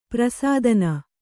♪ prasādana